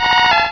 Cri de Phanpy dans Pokémon Rubis et Saphir.